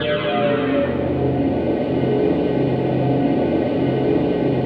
ATMO PAD 04
ATMOPAD04 -LR.wav